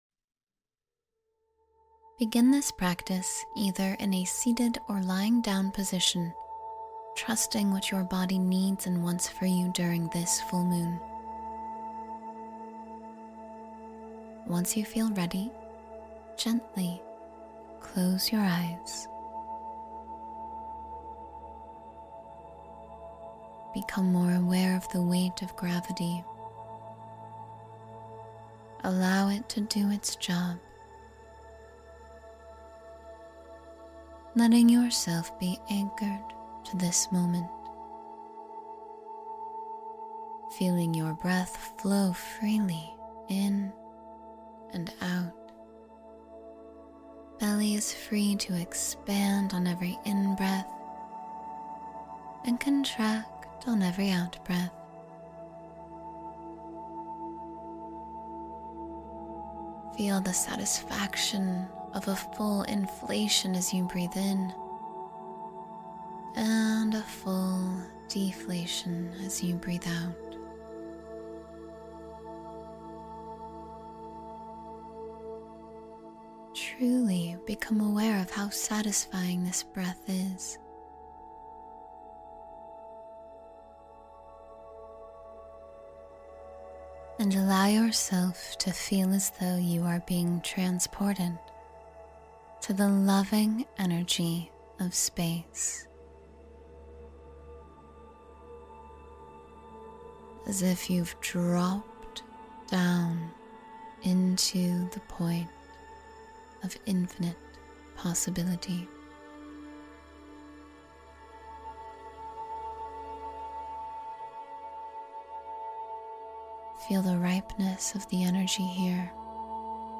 Manifest Your Desires Each Morning — Guided Meditation for Abundance